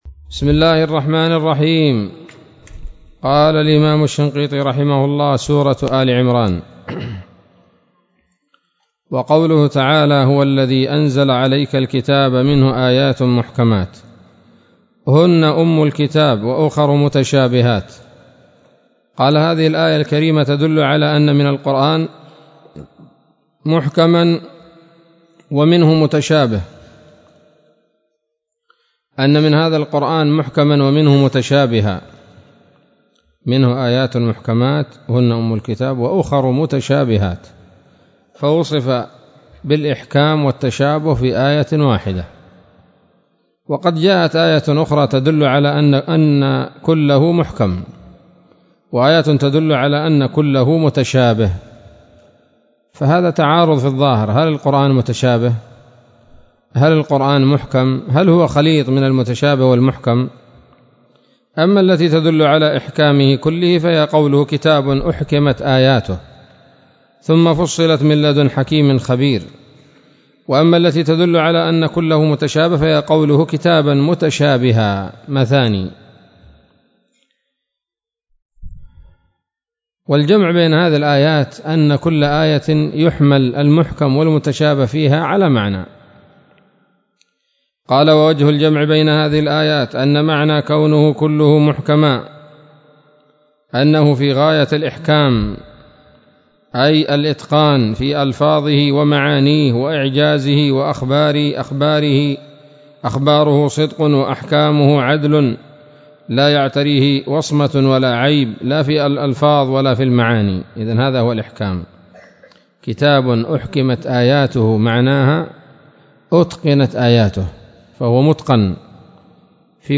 الدرس الحادي والعشرون من دفع إيهام الاضطراب عن آيات الكتاب